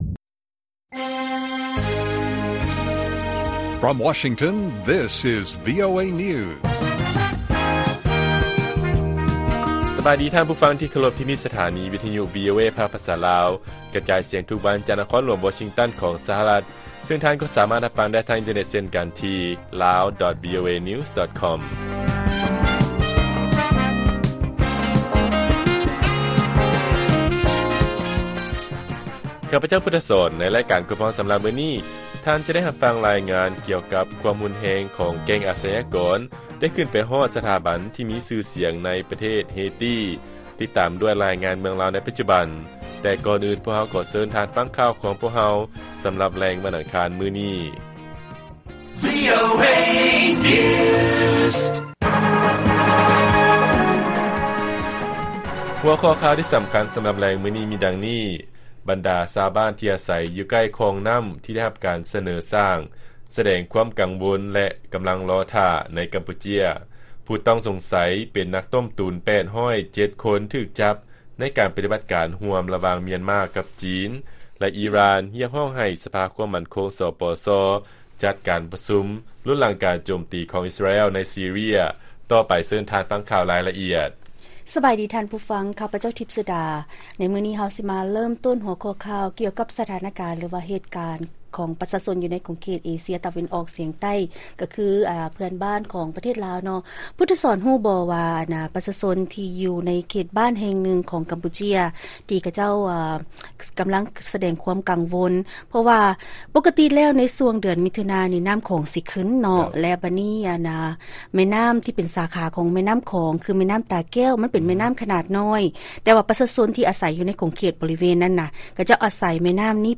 ລາຍການກະຈາຍສຽງຂອງວີໂອເອລາວ: ບັນດາຊາວບ້ານທີ່ອາໄສຢູ່ໃກ້ຄອງນໍ້າທີ່ໄດ້ຮັບການສະເໜີສ້າງ ສະແດງຄວາມກັງວົນ ແລະກໍາລັງລໍຖ້າ